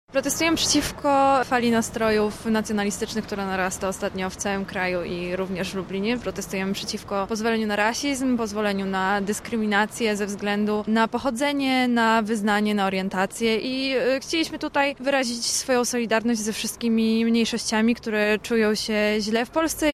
Sobotnia manifestacja Partii Razem przed lubelskim ratuszem odbyła się pod hasłem solidarności z obcokrajowcami. Organizatorzy chcieli zwrócić uwagę lokalnej społeczności na obcokrajowców, którzy spotykają się z agresją spowodowaną ich pochodzeniem.
Demonstracja antyrasistowska